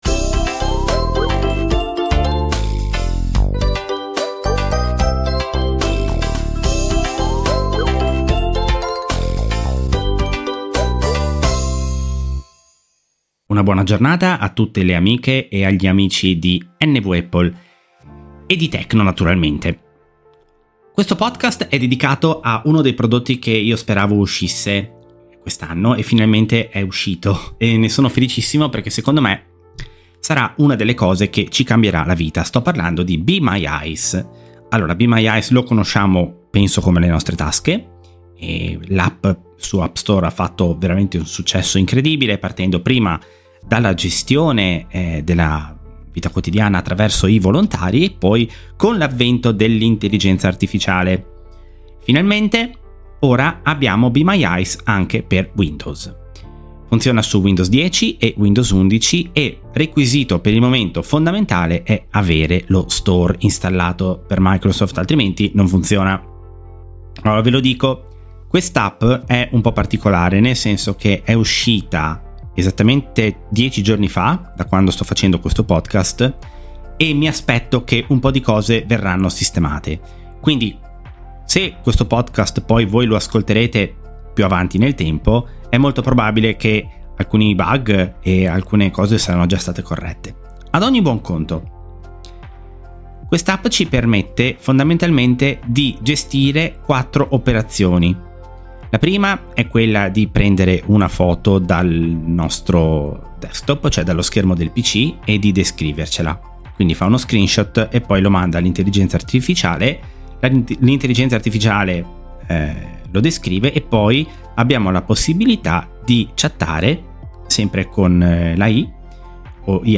Arriva be my eyes per Windows, dimostrazione pratica